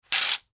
Звуки счётов